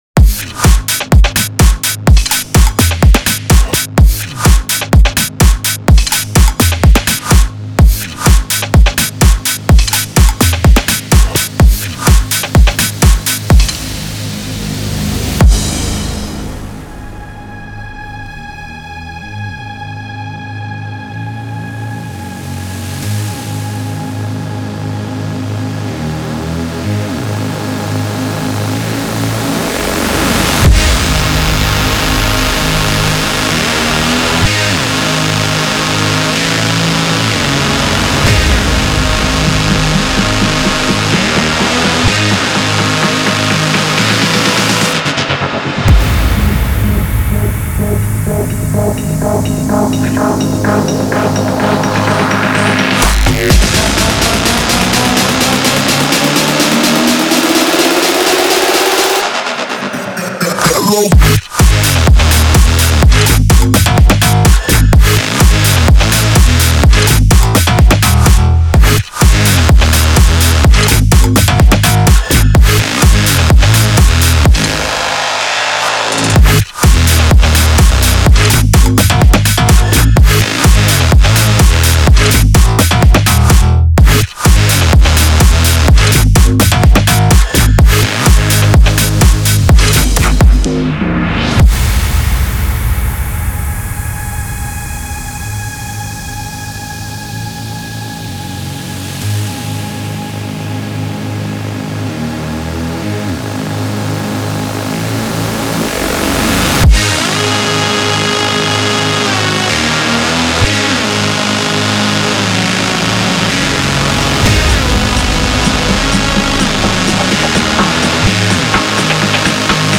Future House, Dark, Energetic, Angry, Gloomy